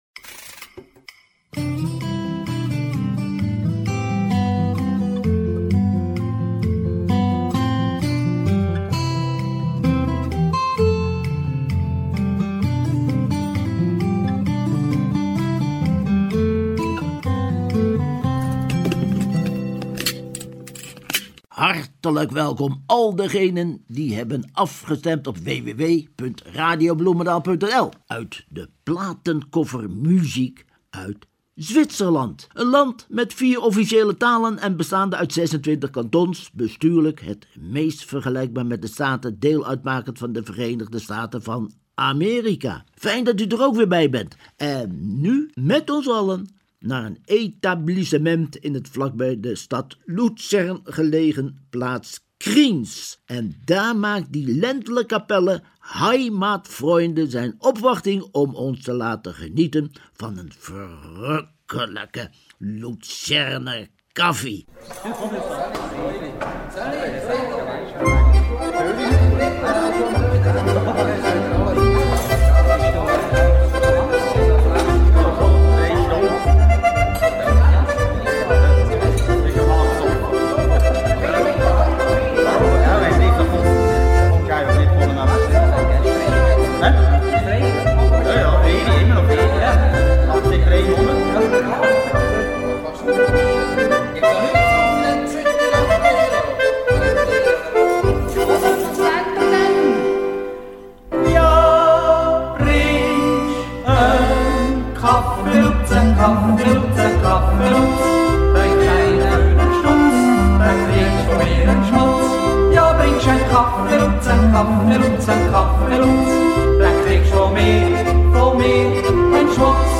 Niet alleen jodelen maar ook alpenhoorns en Ländler muziek kunnen typisch Zwitsers zijn.
Variaties op verschillende toonhoogten van de stemklanken zijn sfeerbepalend en vooral kenmerkend in de Zwitserse muziek.
Ook hoort u zang in diverse, in Zwitserland gesproken, talen en dialecten.